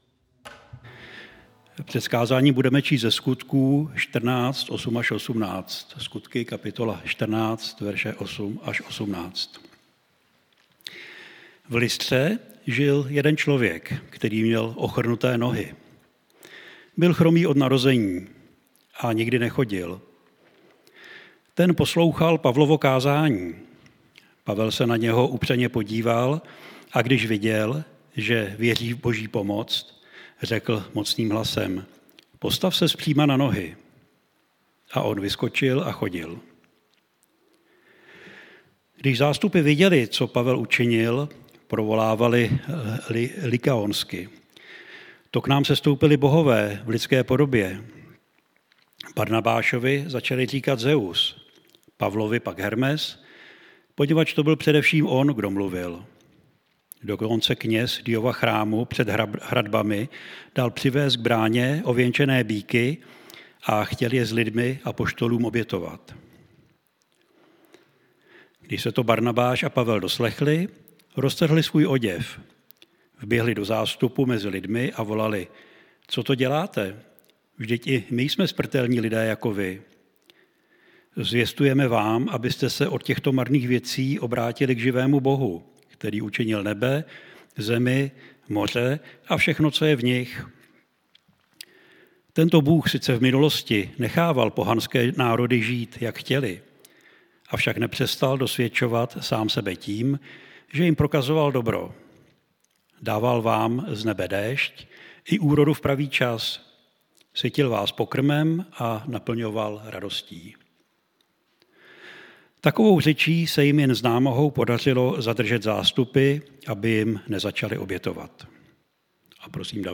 Kategorie: Nedělní bohoslužby